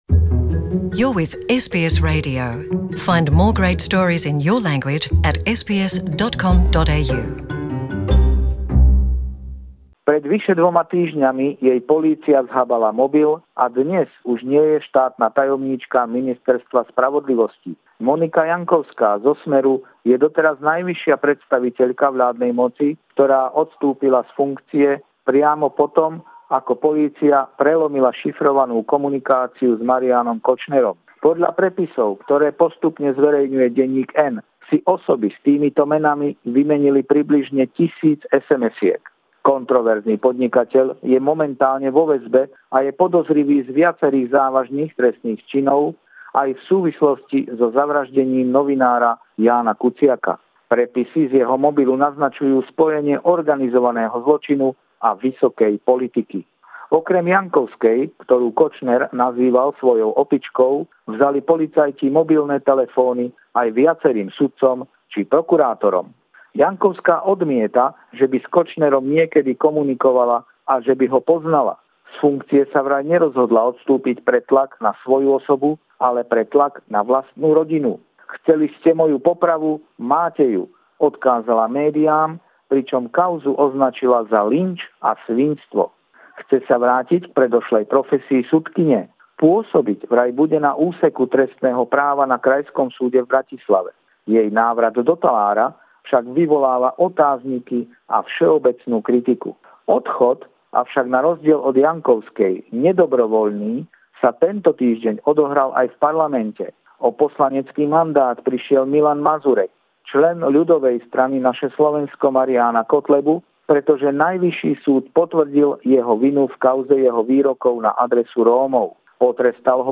Pravidelný telefonát